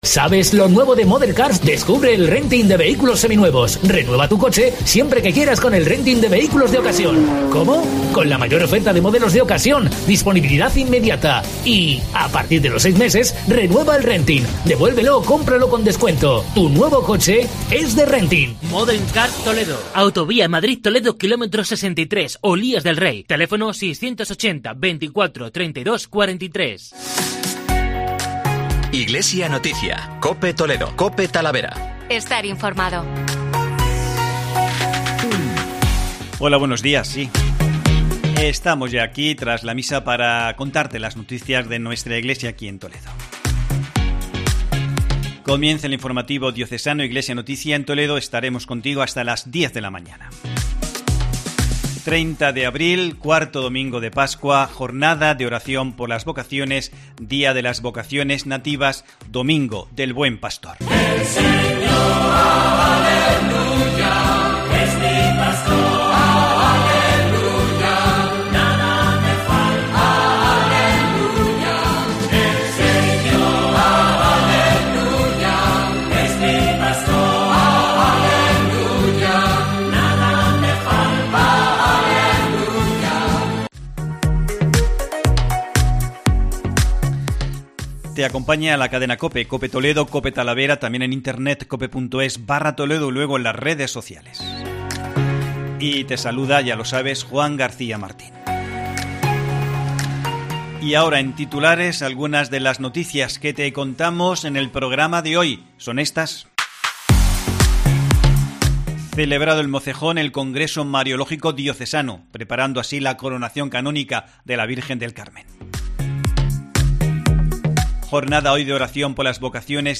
Informativo Diocesano Iglesia en Toledo